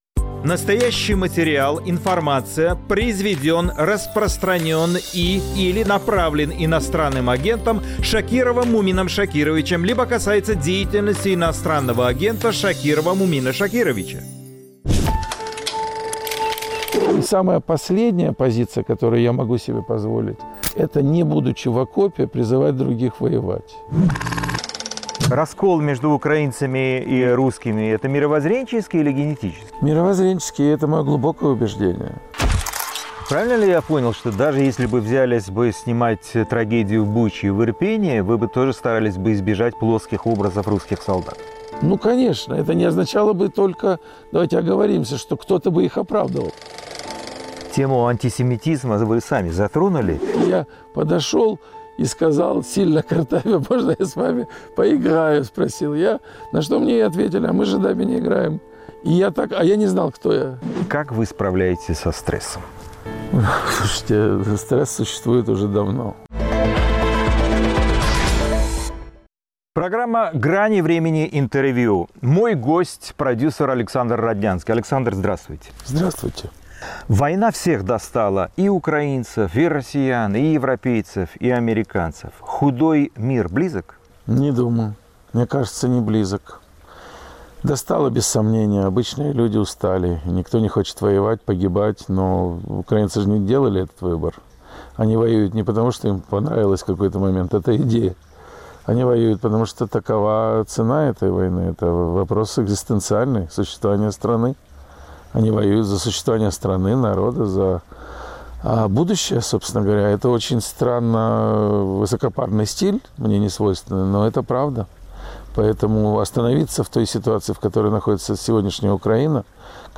Кинопродюсер Александр Роднянский – в программе "Грани времени. Интервью".